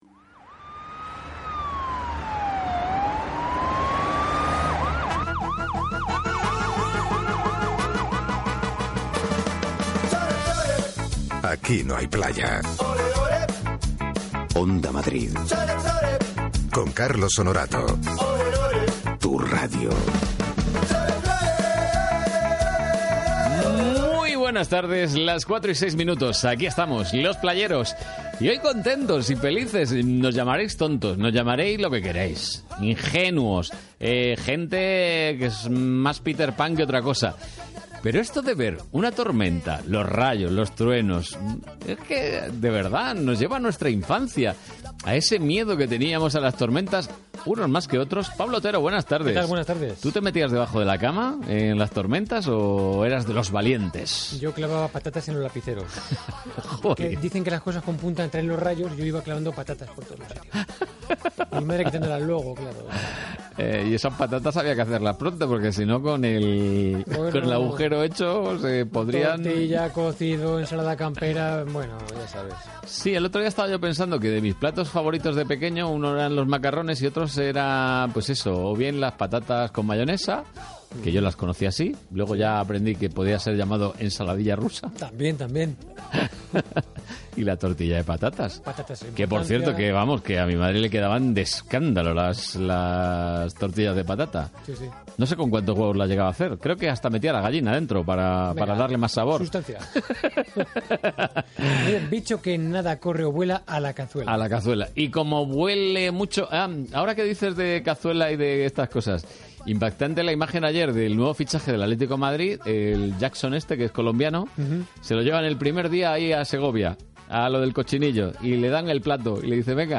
La verdad es que fue una entrevista muy amena, ligera y también muy sustanciosa….